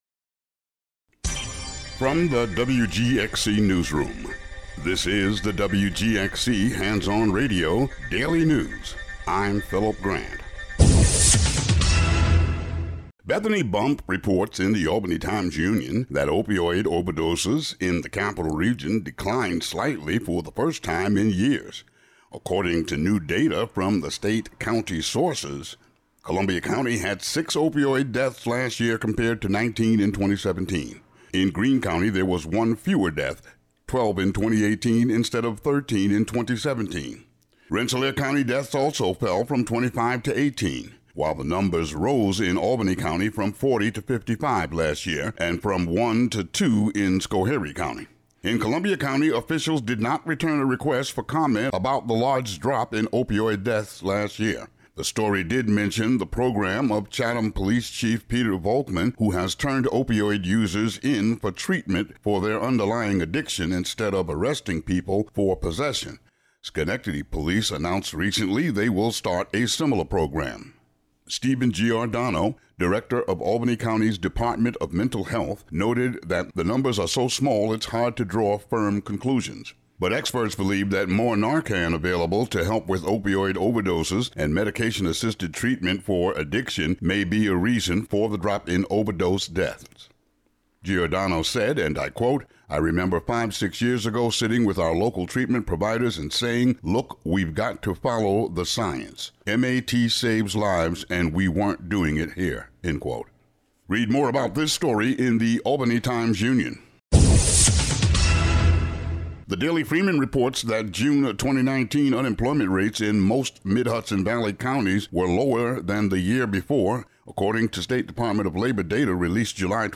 WGXC Local News Audio Link